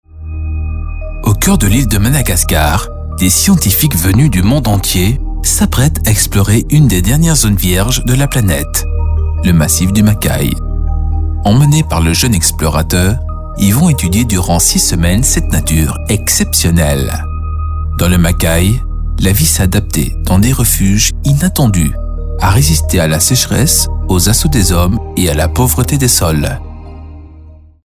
Voix off
Démo de ma voix reportage
🎈Fort d’une expérience de 14 ans dans le domaine de la voix off, j’ai réalisé diverses prestations professionnelles partout dans le monde depuis mon home-studio professionnel.